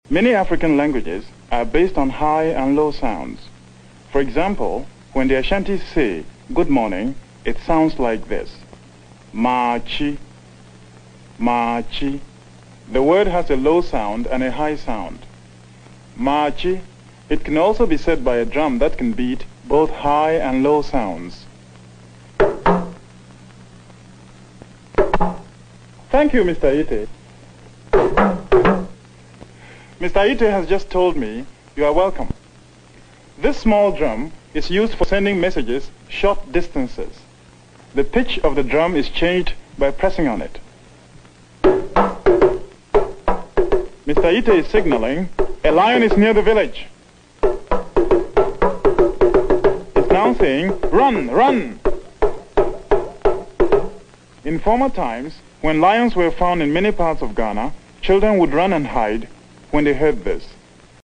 Il tamburo parlante, introdotto dall'antico sound effects free download